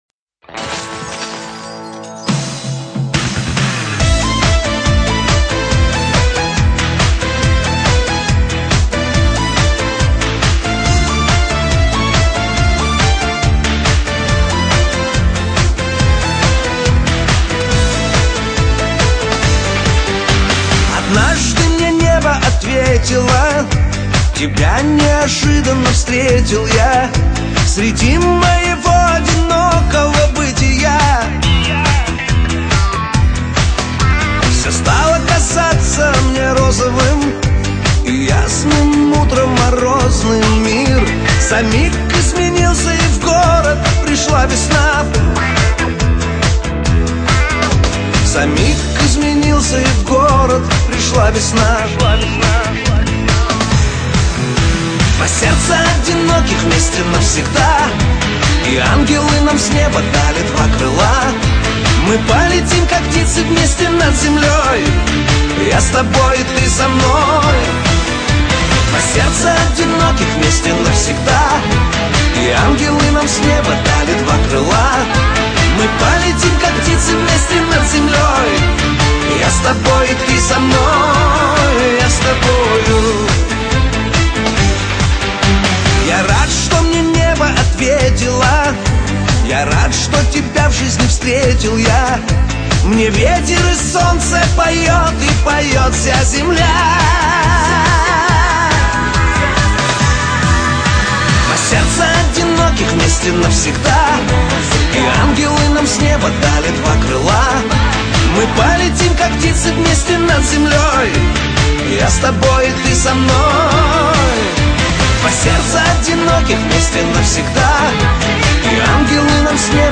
музыка шансон